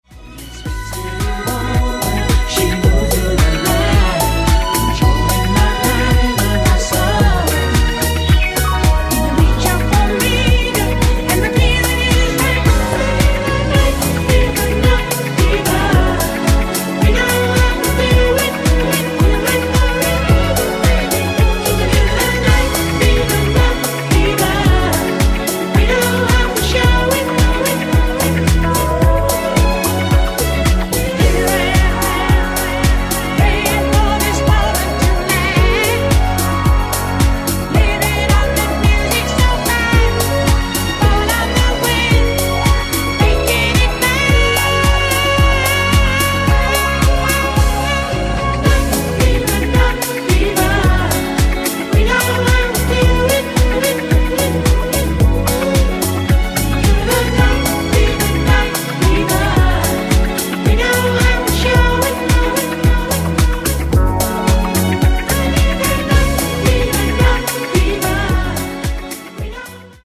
NU-Disco Remix